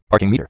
A spearcon is a brief non-speech sound that is created by speeding up the TTS phase in particular ways, even to the point where the spearcon is no longer recognizable as a particular word. Often a spearcon is prepended to each TTS menu item, and leads to faster, more accurate, and more enjoyable navigation through a menu.
Spearcon samples: [elephant.wav] [elevator.wav] [
parkingmeter.wav